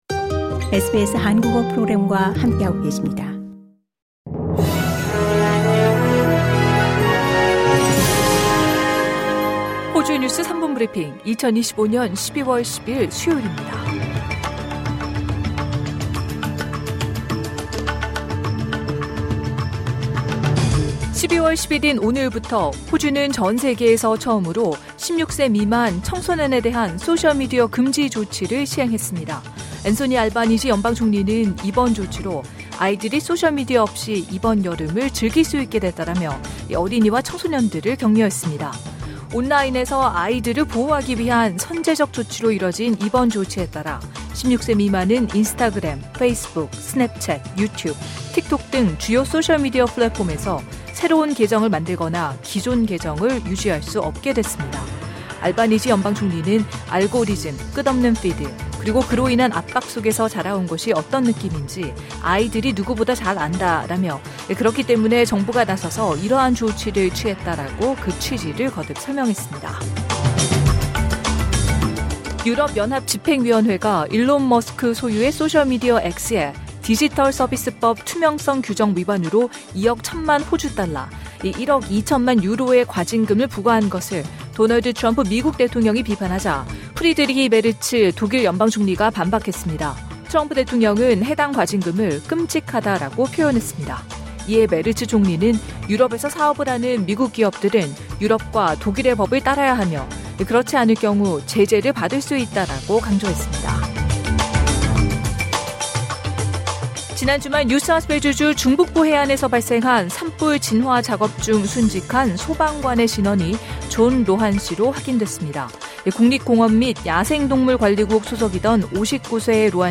호주 뉴스 3분 브리핑: 2025년 12월 10일 수요일